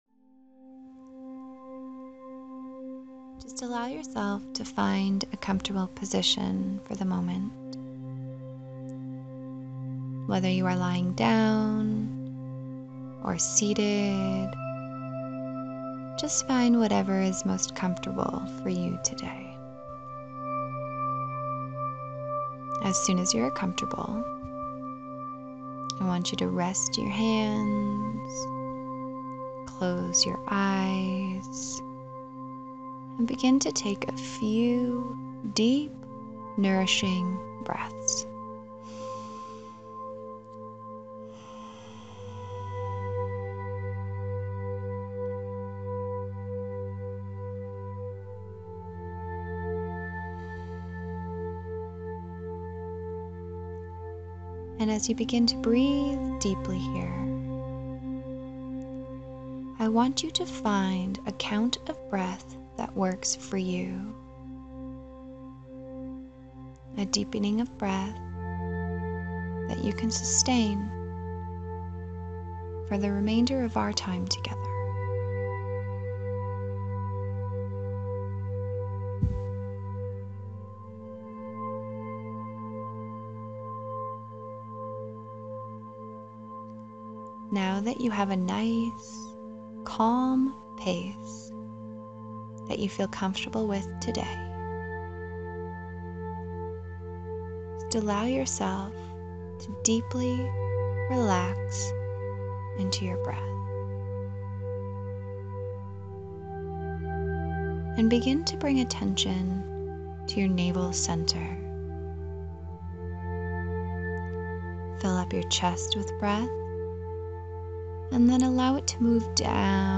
Meditation-Light-and-Vision.mp3